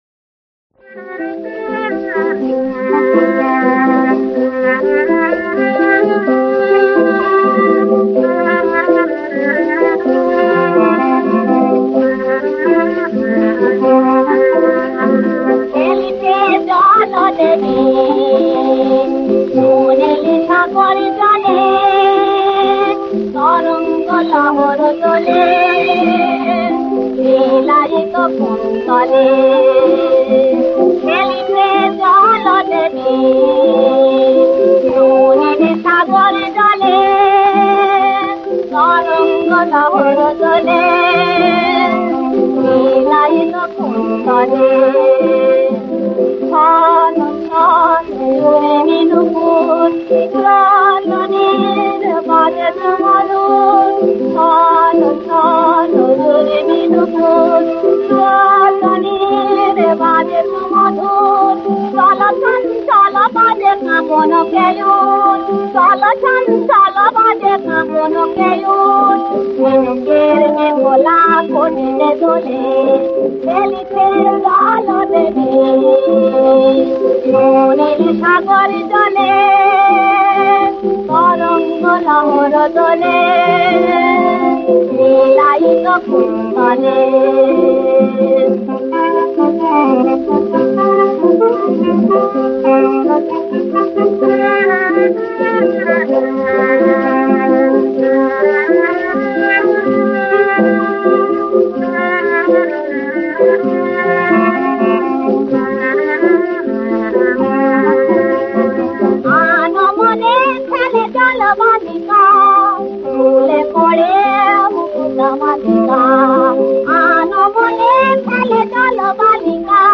• সুরাঙ্গ: স্বকীয় বৈশিষ্ট্য
• তাল: কাহারবা